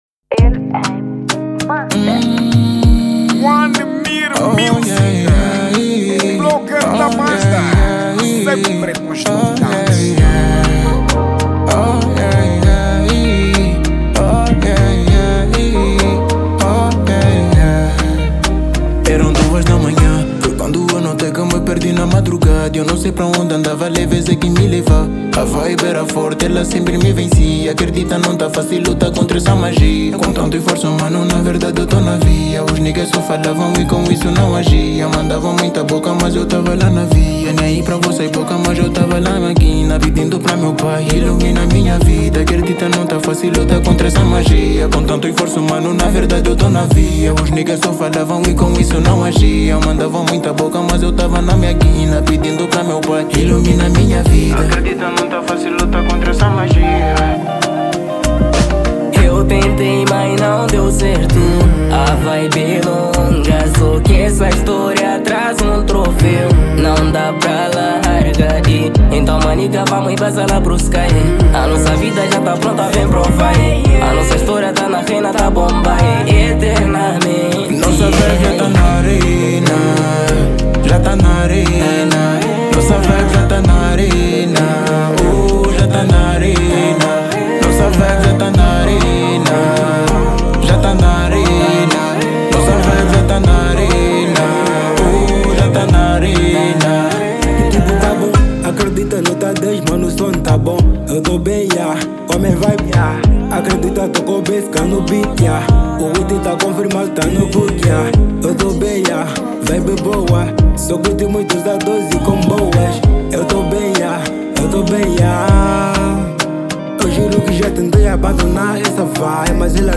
Kizomba R&B
Gênero : R&B